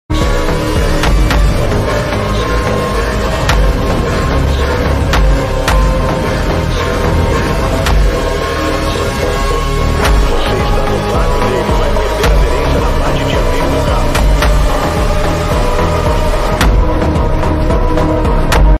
Lotus E20! V8 Sound 🔥 sound effects free download